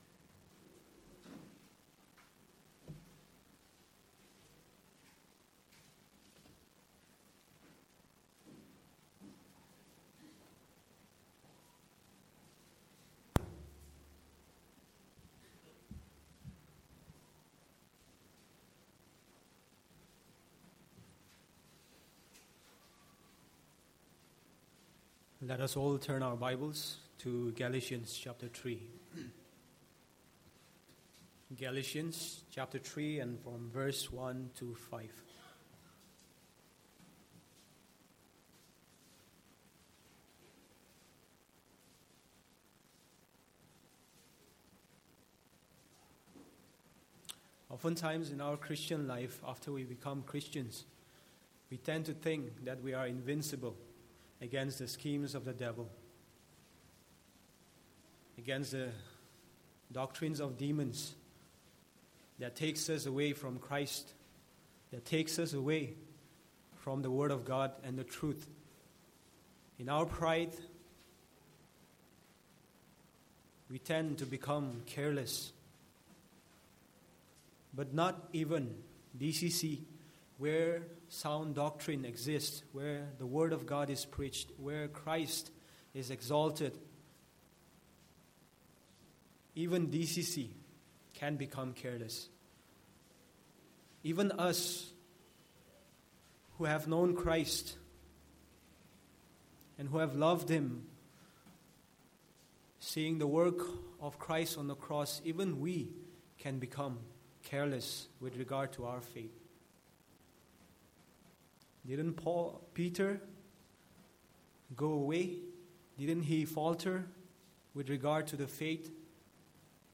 Passage: Galatians 3:1-5 Service Type: Sunday Morning